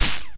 lg_hit3.ogg